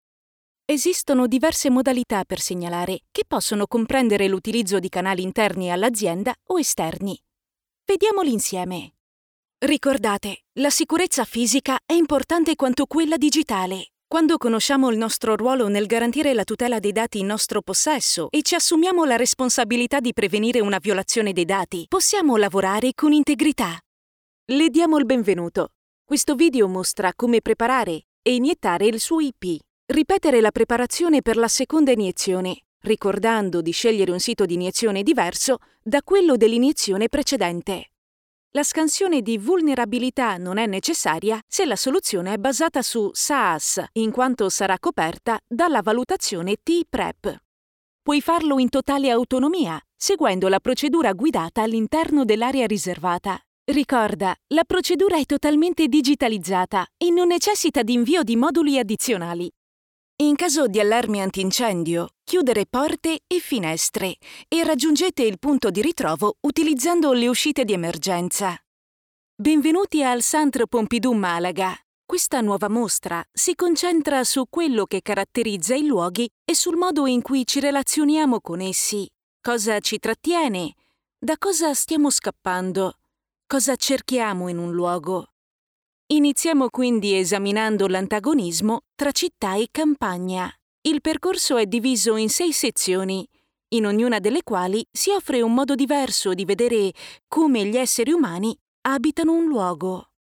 Voce Spot Nazionali | Speaker Pubblicitaria | Italian Voice Talent